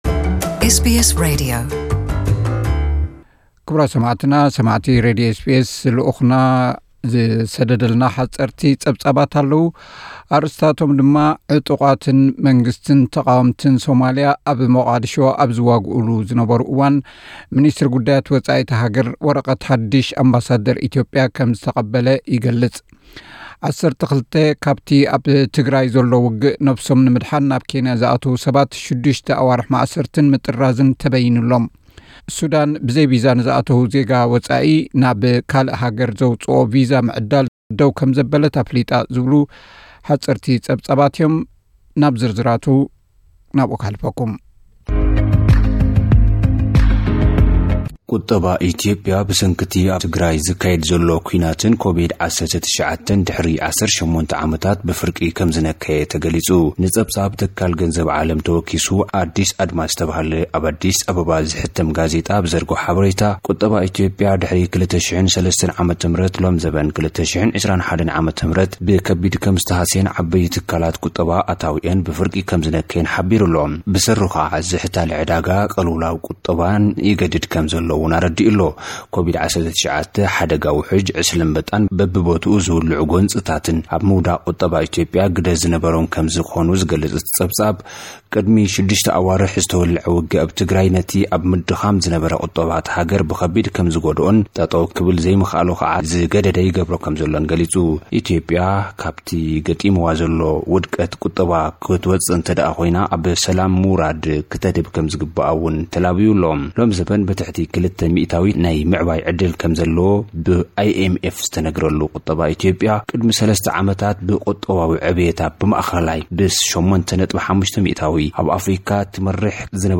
ሓጸርቲ ጸብጻባት
ሱዳን ብዘይቪዛ ንዝኣተወ ዜጋ ወጻኢ ናብ ካልእ ሃገር ዘውጽኦ ቪዛ ምዕዳል ደው ከም ዘበለት ኣፍሊጣ። ዝብሉ ሓጸርቲ ጸብጻባት ልኡኽና ክቐርብዩ።